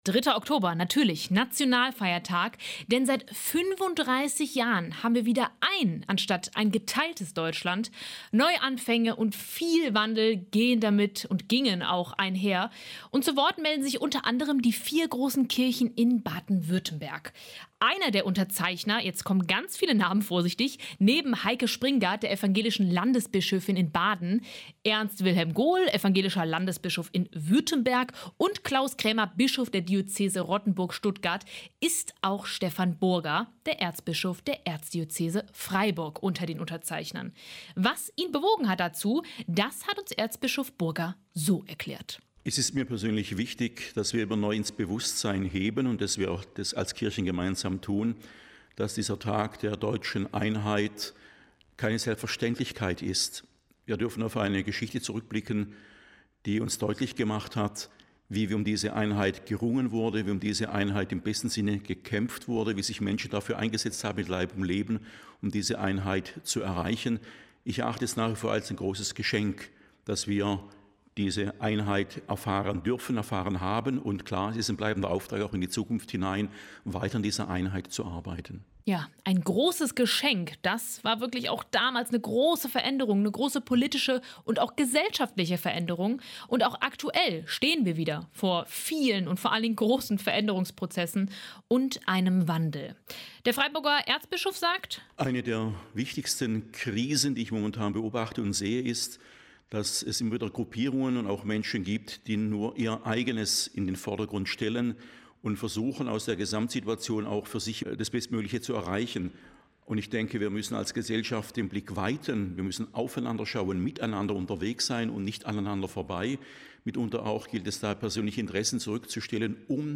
Ein Interview mit Stephan Burger (Erzbischof der Erzdiözese Freiburg)